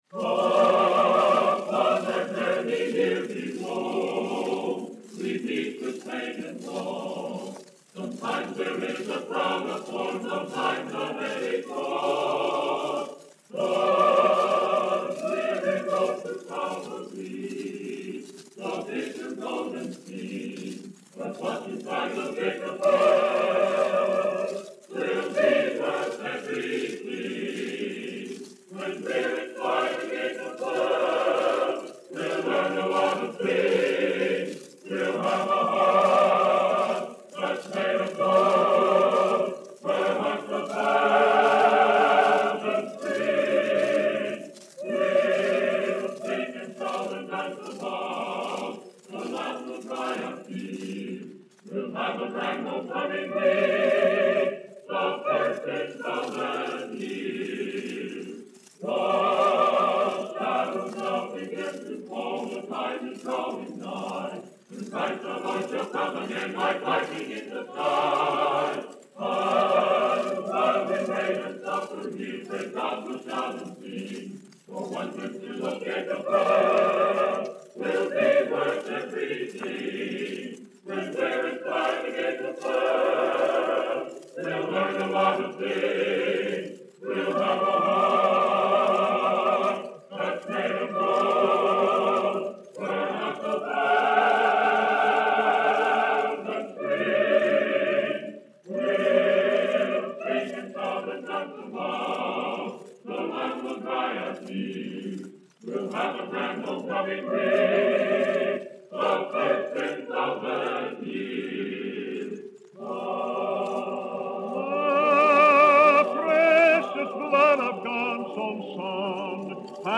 Bethany Peniel College (BPC) A Cappella Choir recording from 1954.
This type of recording medium is considered very fragile and unstable. This recording has been transferred using special phonograph styli improving sound